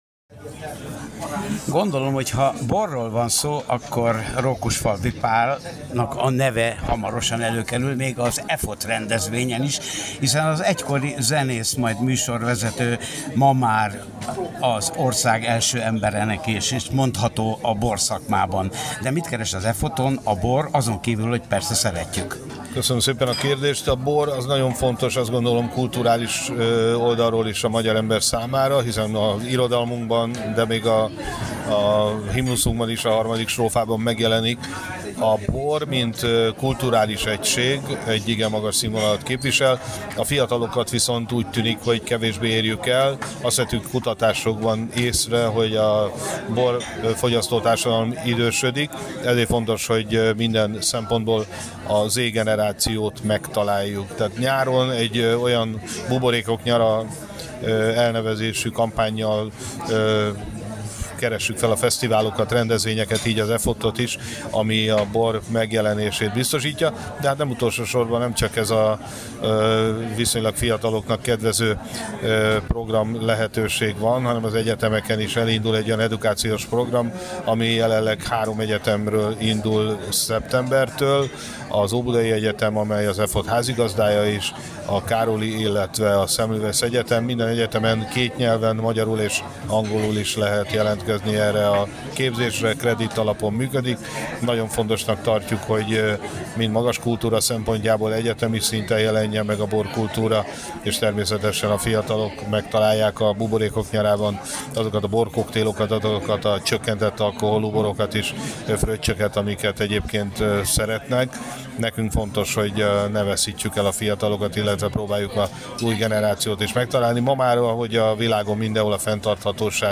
Interjú Rókusfalvy Pállal, a nemzeti bormarketing kormánybiztosával
Készült a 2024-es EFOTT (július 10-14) sajtótájékoztatóján.